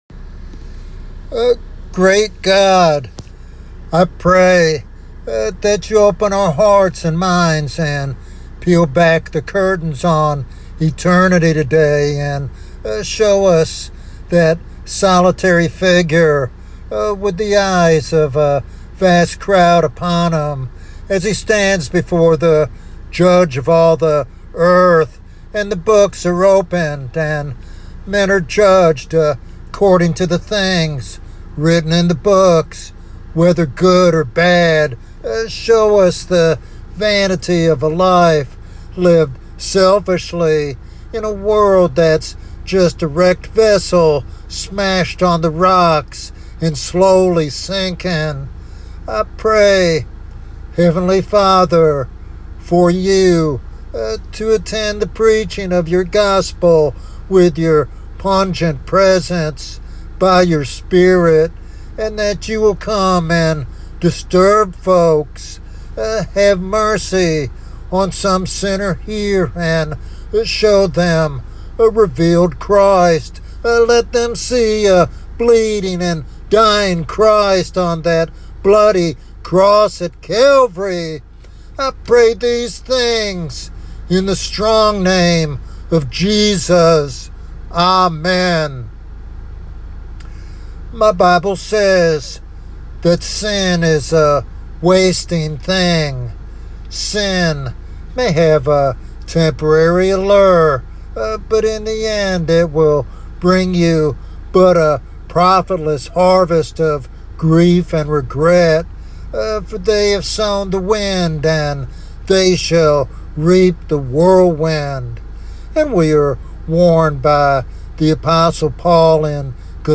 This evangelistic sermon invites all to come to Christ and receive the living water of grace before the final judgment.